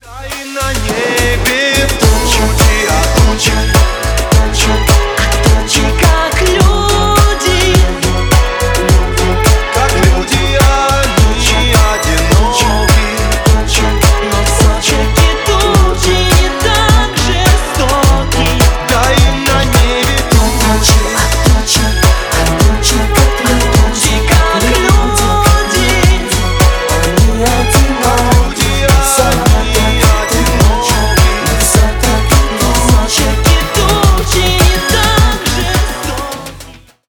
Ремикс # Поп Музыка
грустные